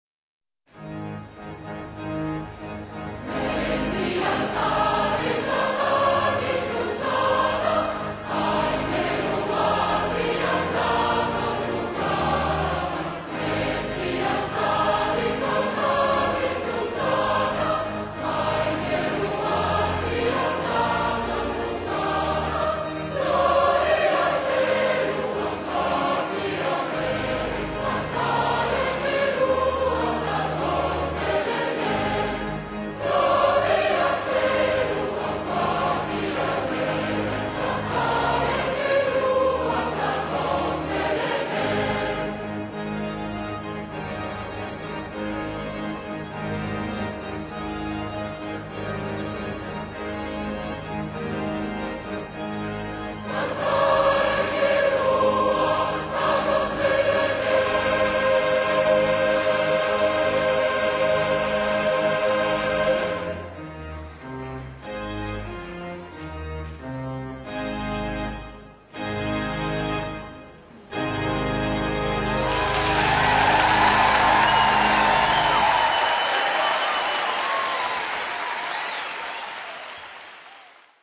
Christmas
* performed by 12 choirs   midi